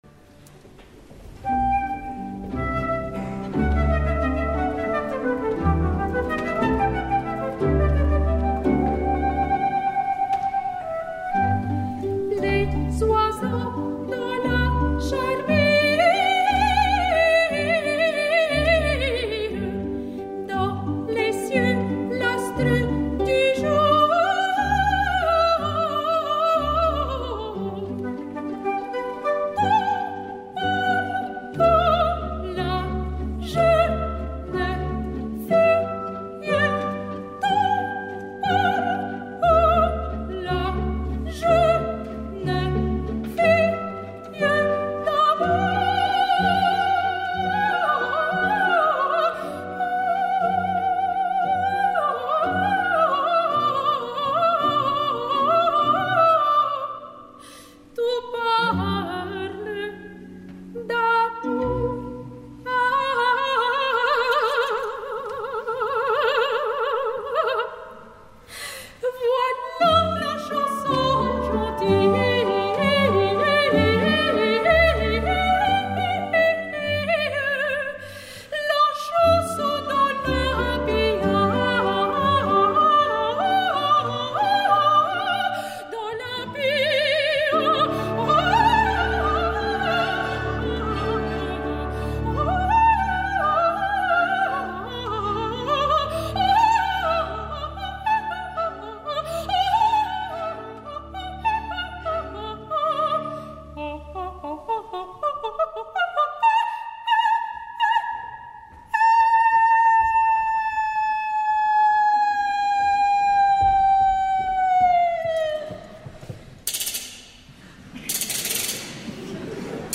És una soprano lírica amb un registre magnífic que assoleix les zones greus també necessàries per Antonia i Giulietta, i que per assolir la temible Olympia (sense els sobreaguts amb que les sopranos lleugeres l’ornamenten) llueix un registre agut brillantíssim.
Per començar a Yoncheva a “Les oiseaux dans la charmille” d’Olympia
Versió de concert
Sonya Yoncheva, soprano (Olympia/Antonia/Giulietta/Stella)
22 de novembre de 2012, Salle Pleyel de Paris